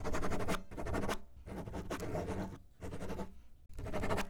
Writing (1).wav